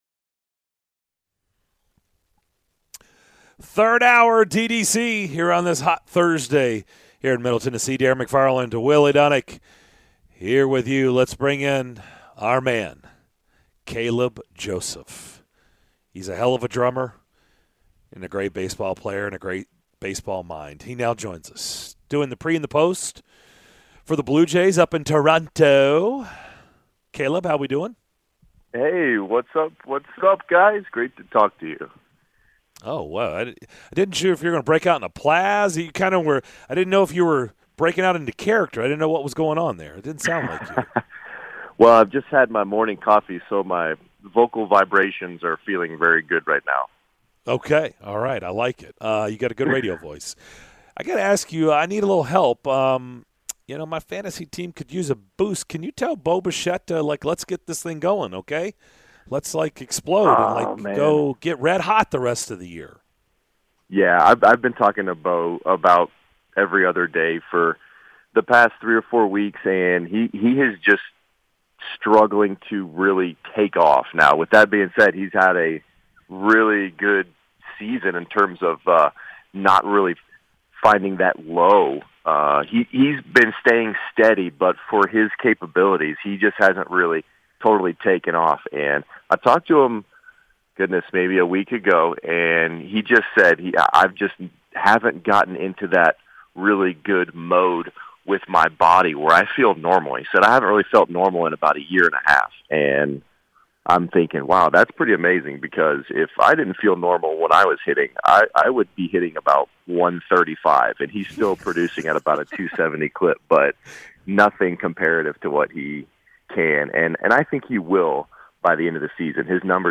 Caleb Joseph Full Interview (07-07-22)